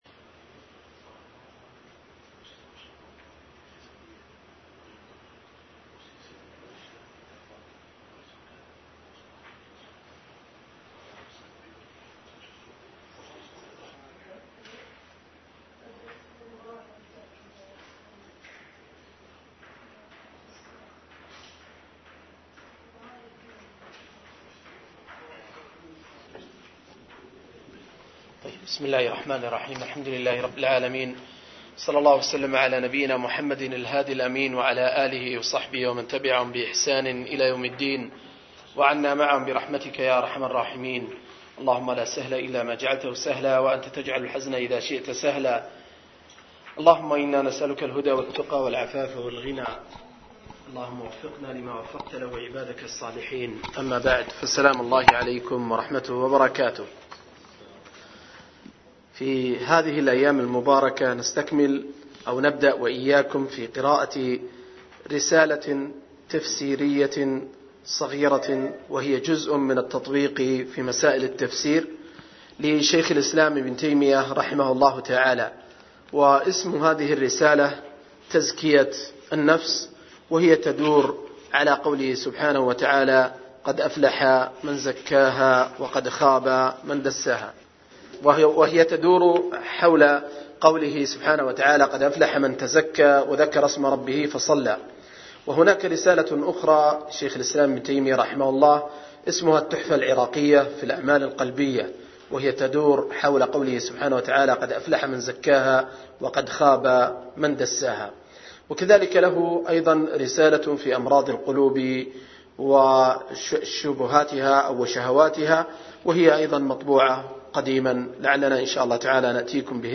01- رسالة تزكية النفس لشيخ الإسلام – قراءة وتعليق – المجلس الأول